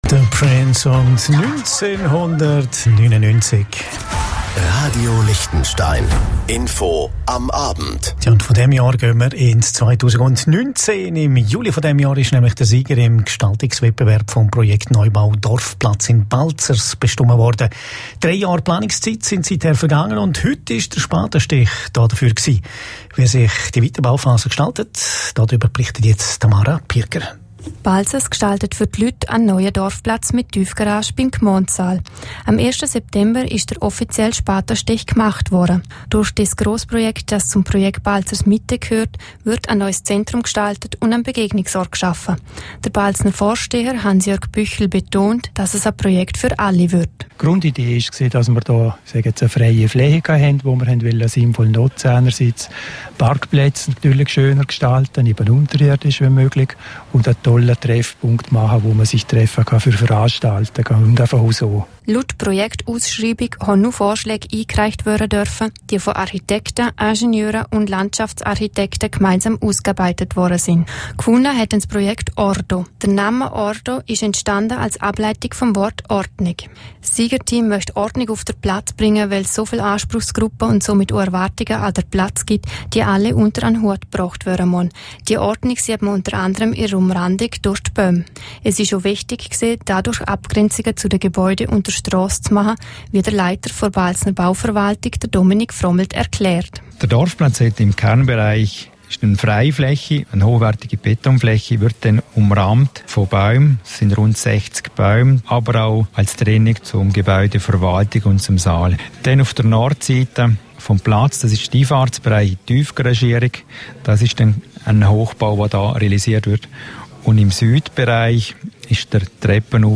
Bericht Radio L